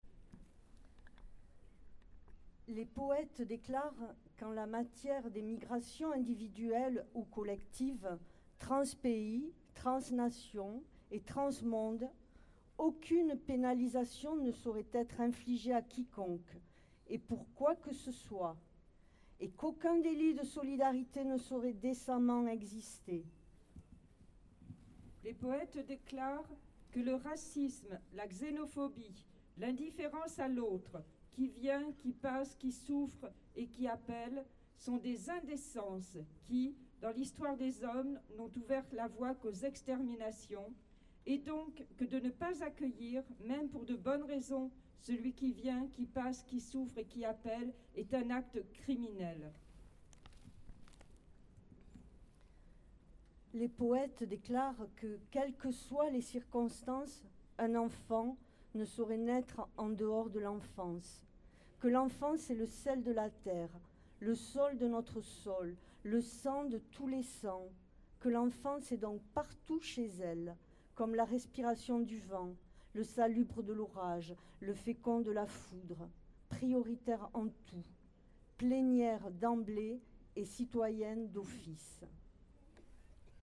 Deux manifestantes prennent alors le micro, pour lire les mots de l’écrivain Patrick Chamoiseau.
lecpoeme